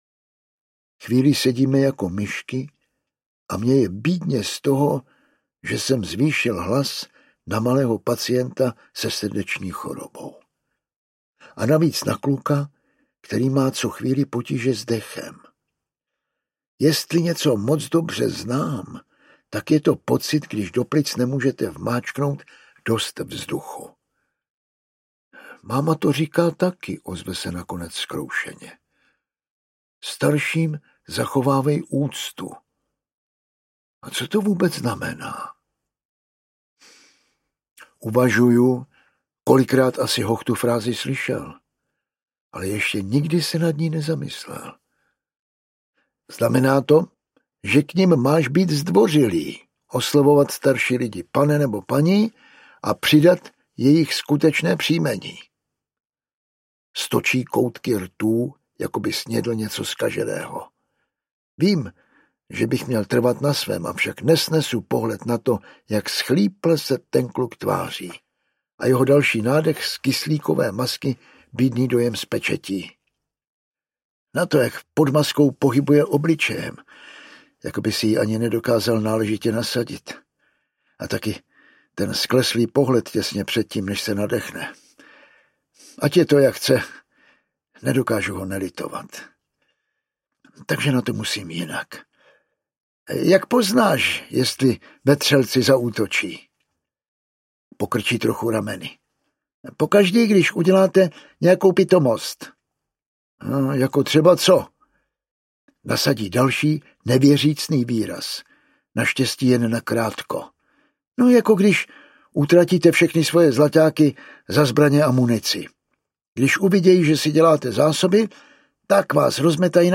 Pět přání pana Murrayho McBridea audiokniha
Ukázka z knihy
Čte Jan Vlasák.
Vyrobilo studio Soundguru.